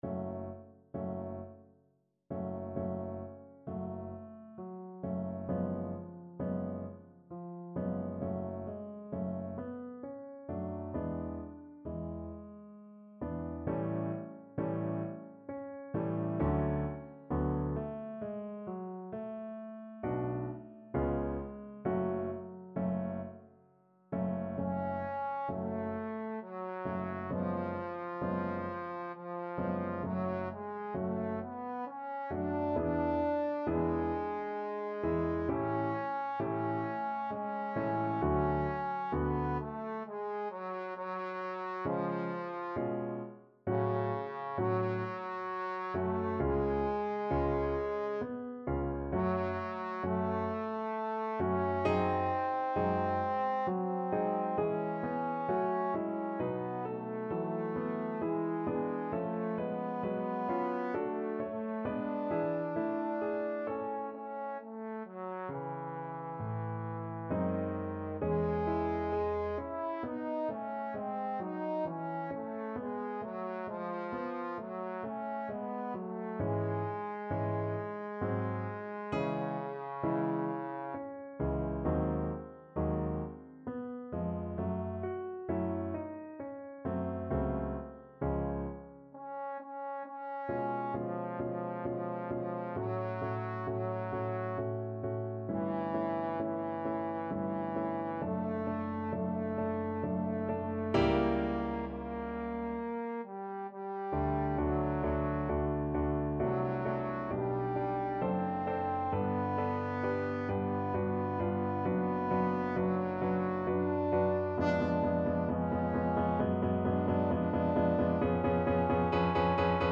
Trombone Classical